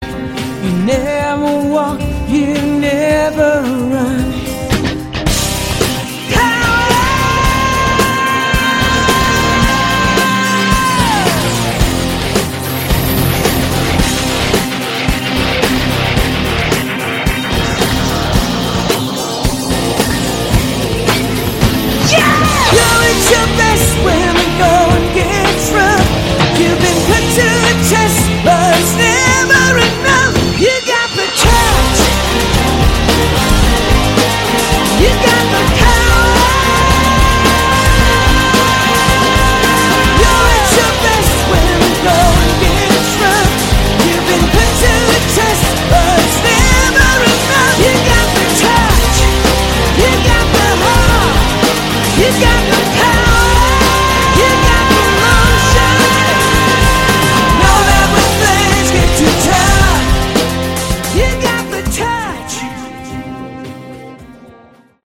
Category: AOR
vocals
guitar, keyboards, bass, drums